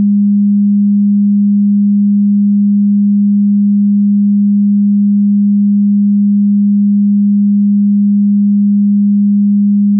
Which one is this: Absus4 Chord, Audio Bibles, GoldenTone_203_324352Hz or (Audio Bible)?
GoldenTone_203_324352Hz